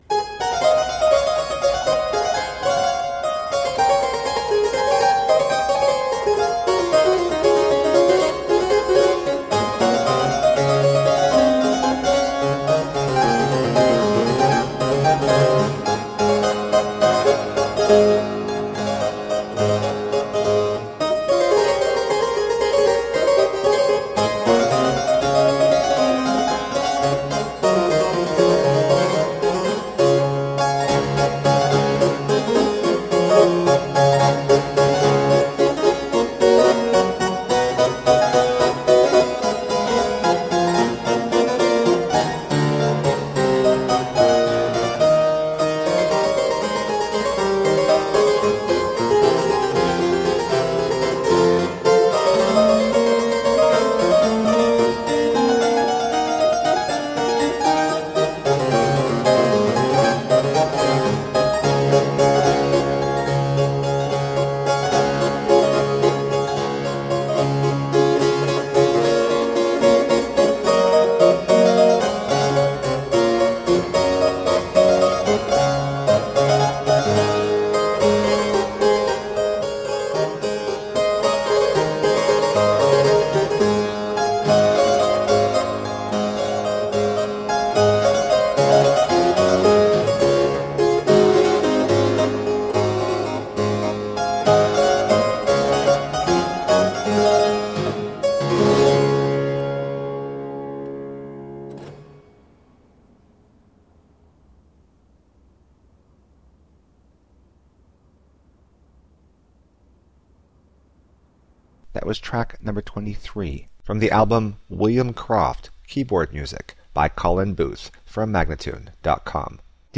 Solo harpsichord music.
Classical, Baroque, Instrumental
Harpsichord